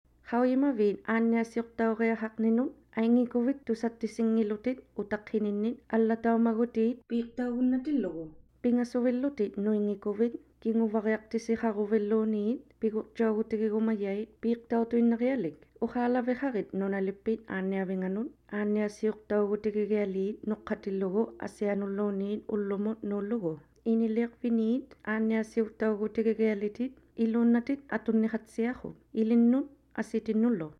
Scheduled to air on TNI through the month of February, t hese three short messages explain: